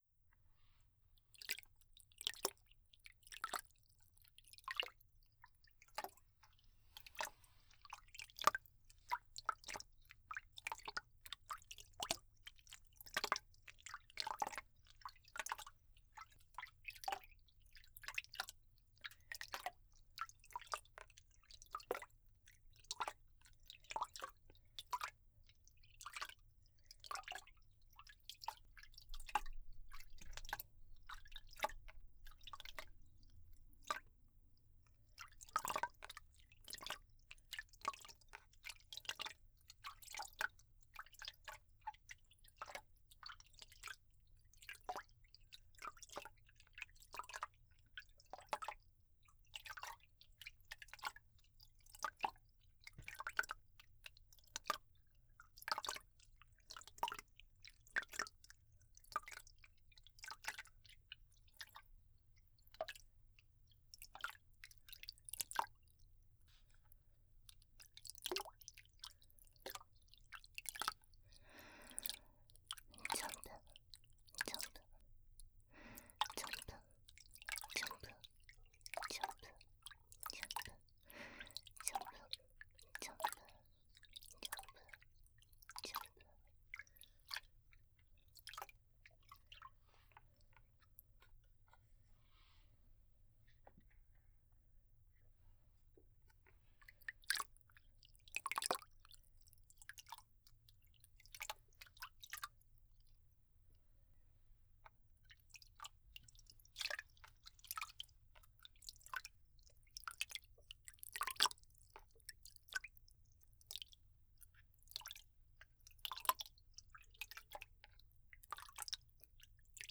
02.水音パートのみ.wav